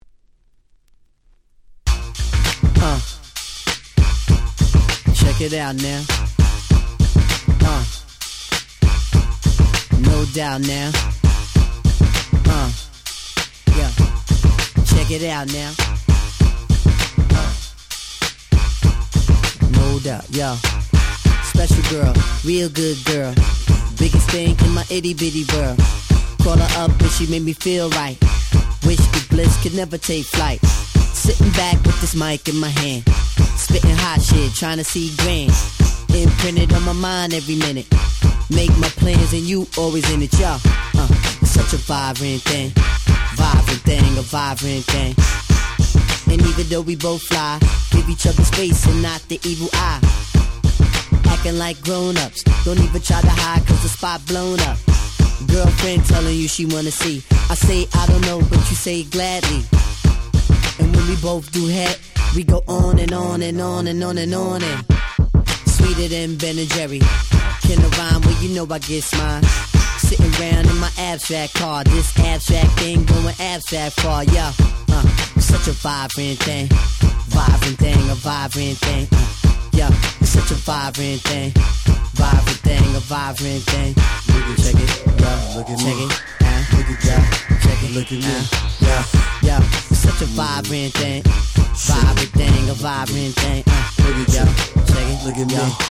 Late 90's Hip Hop Classics !!